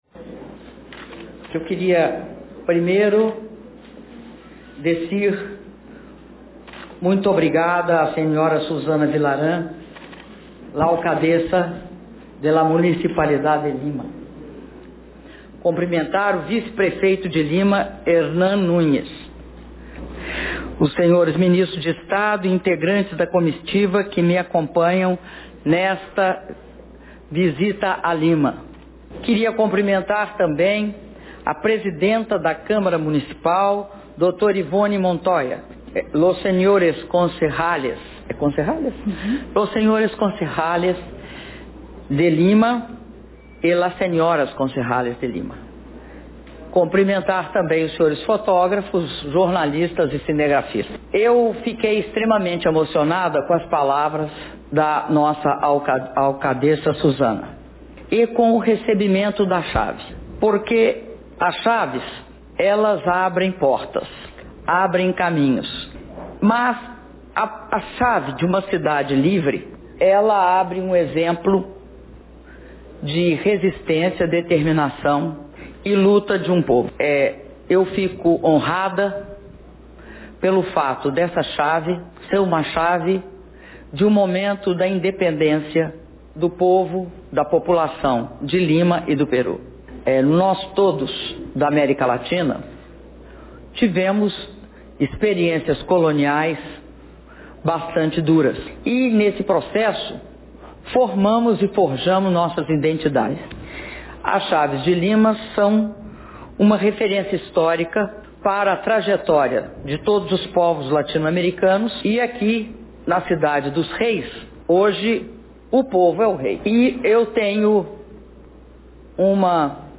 Áudio do discurso da Presidenta da República, Dilma Rousseff, durante cerimônia de entrega das chaves da cidade de Lima - Lima/Peru